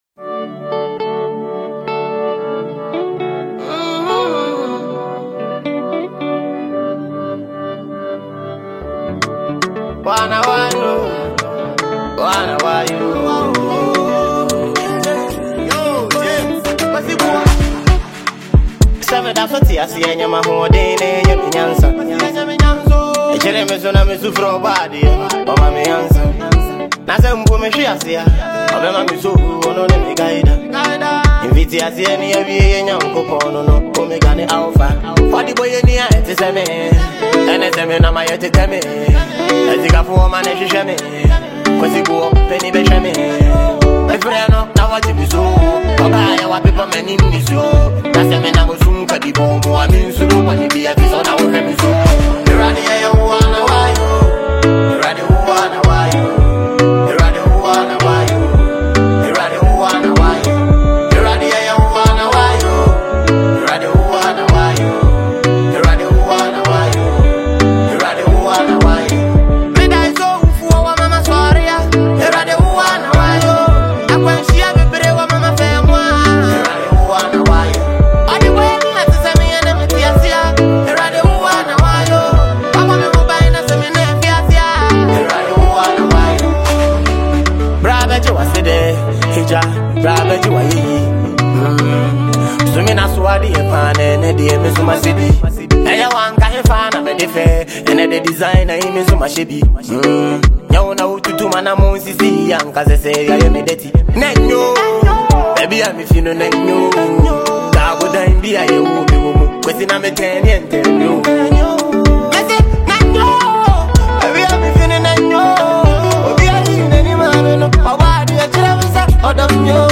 Ghanaian singer and songwriter